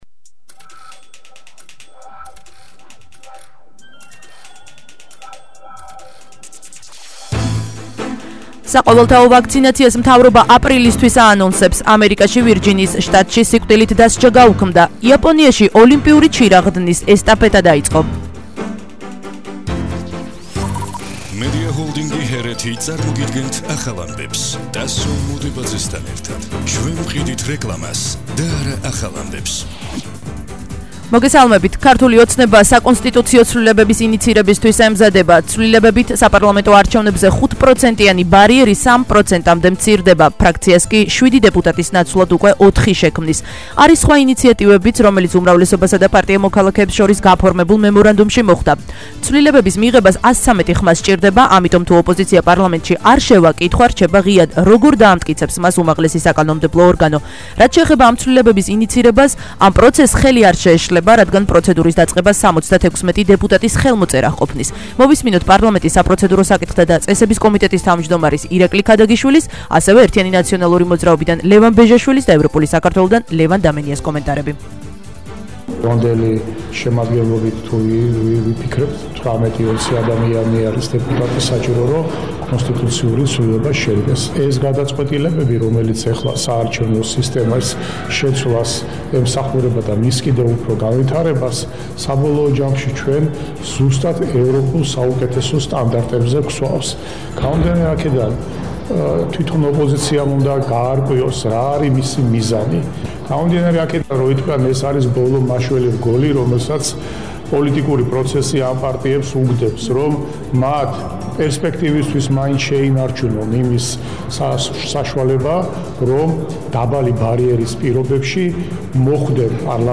ახალი ამბები